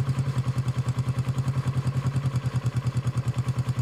motorbikeEngine.wav